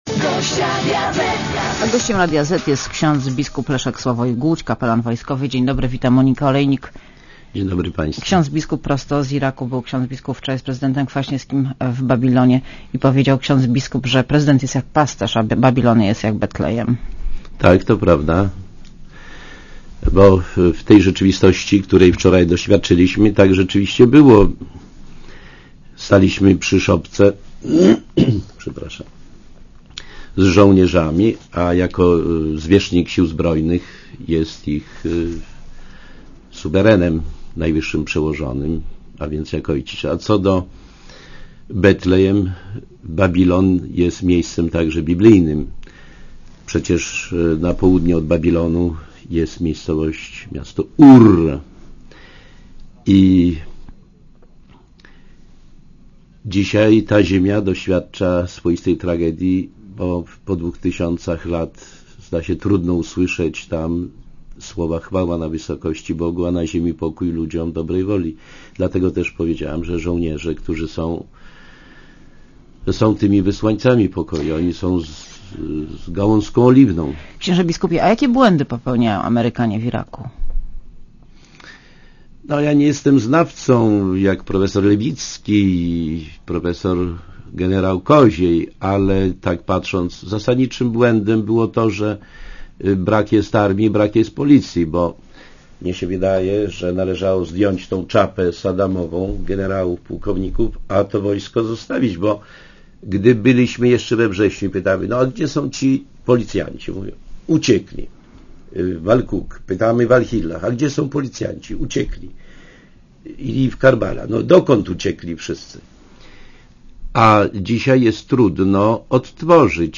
Gościem Radia Zet jest ks. biskup Sławoj Leszek Głódź, kapelan Wojska Polskiego. Wita Monika Olejnik.